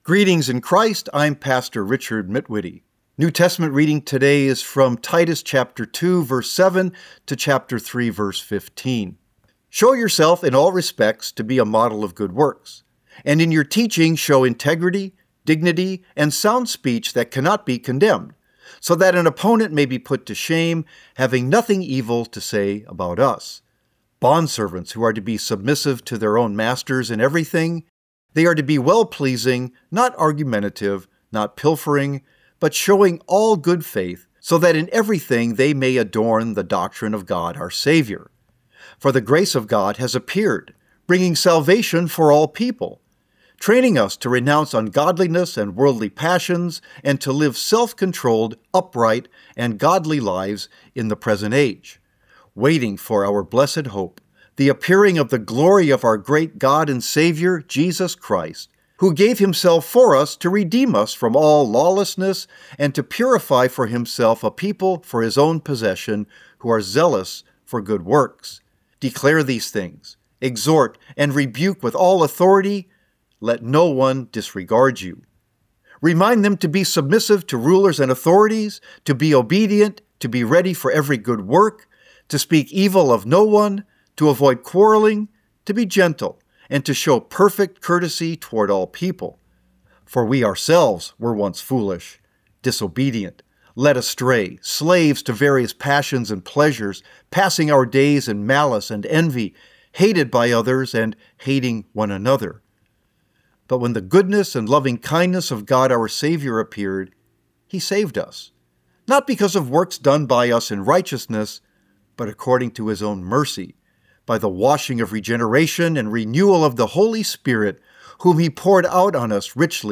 Morning Prayer Sermonette: Titus 2:7-3:15
Hear a guest pastor give a short sermonette based on the day’s Daily Lectionary New Testament text during Morning and Evening Prayer.